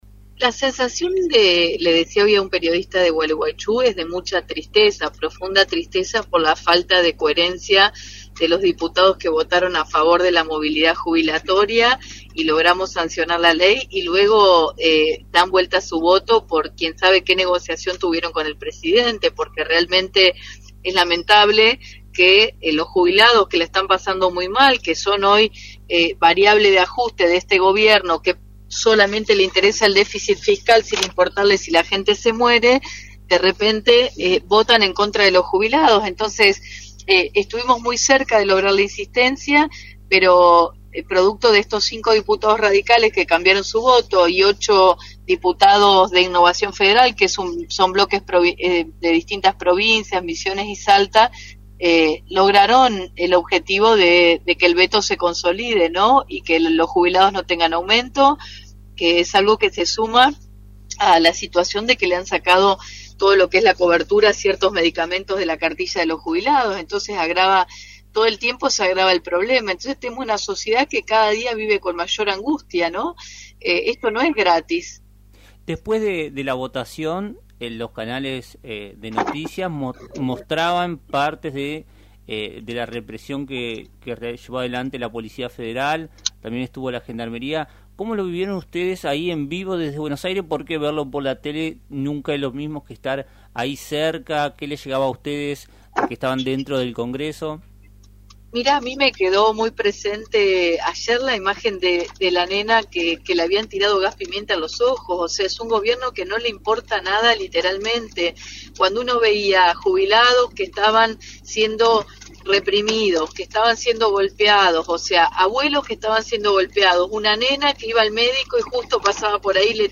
La diputada de la Nación, Carolina Gaillard, compartió con LT 39 Am980 su frustración ante la reciente votación que impidió un aumento para los jubilados.
Carolina Gallard – Dip. Nacional